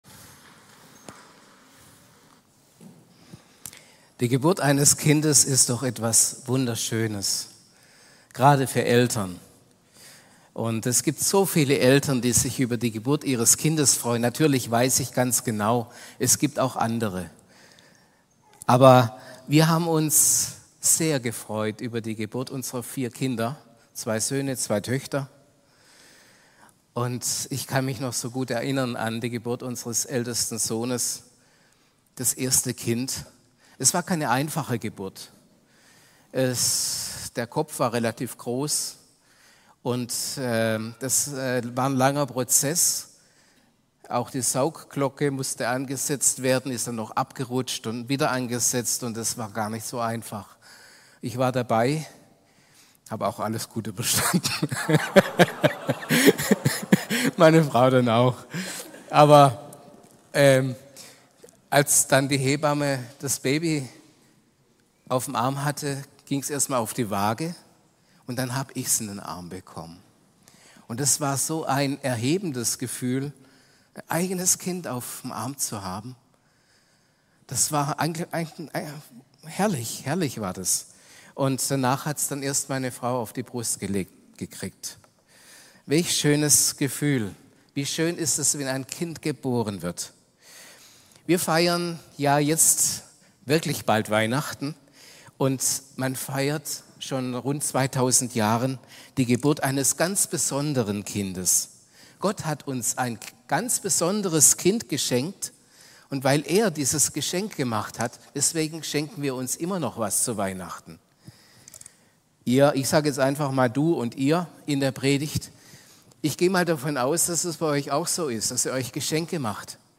Predigt-am-22.12-online-audio-converter.com_.mp3